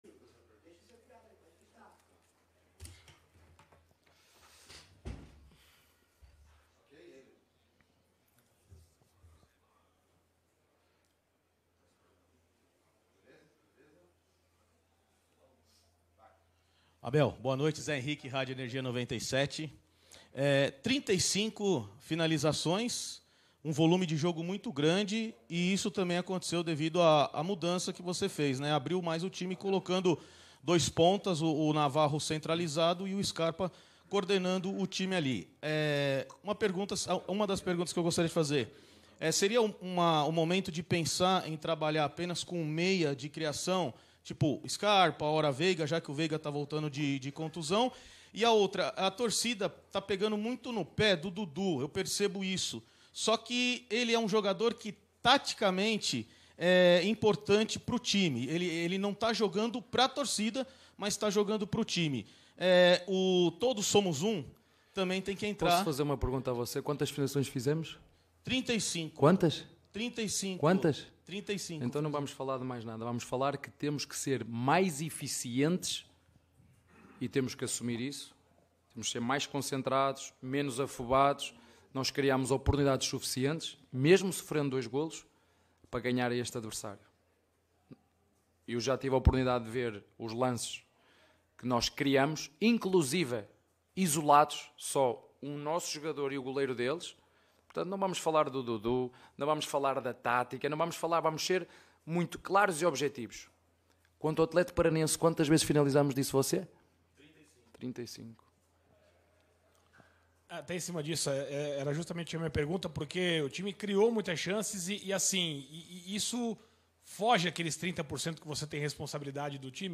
COLETIVA-ABEL-FERREIRA-_-PALMEIRAS-X-ATHLETICO-_-BRASILEIRO-2022.mp3